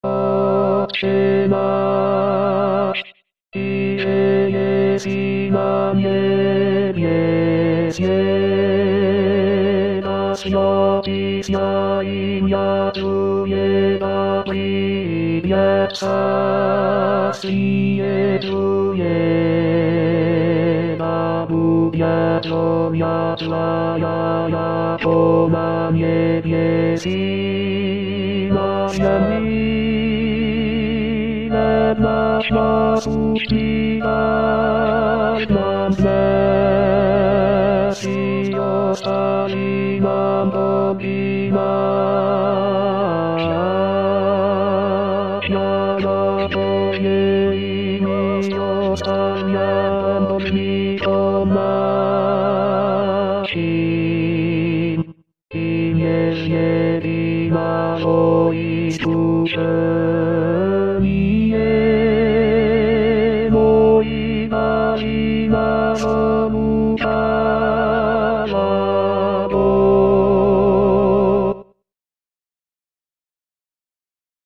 Tenor.mp3